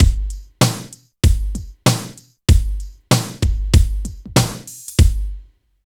28 DRUM LP-R.wav